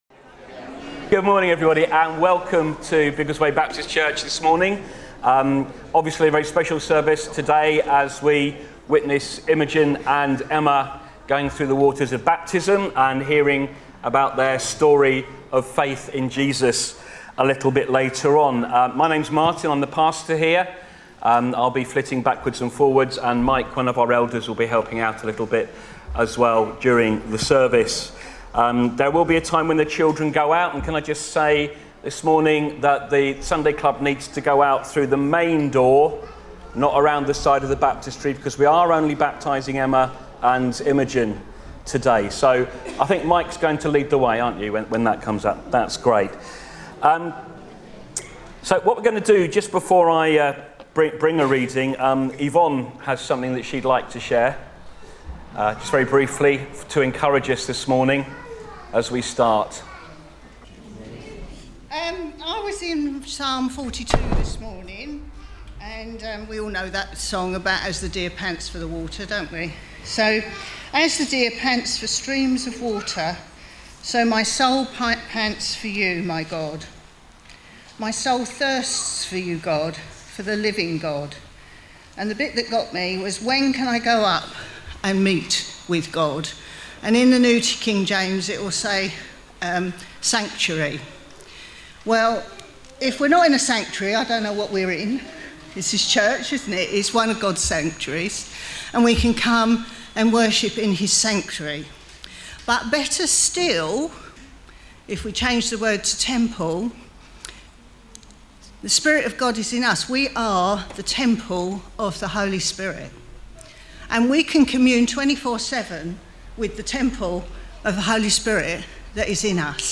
13 July 2025 – Morning Baptismal Service
The service also includes the believers' baptism of two of our church family.